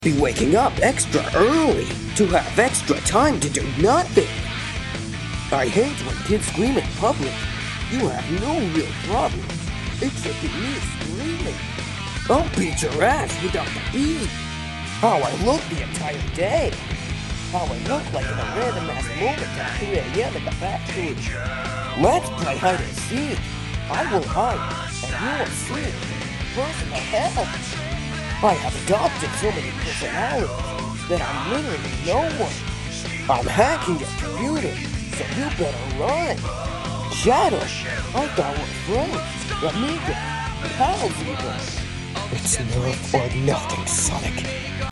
Random Sonic Voice Acting Meme sound effects free download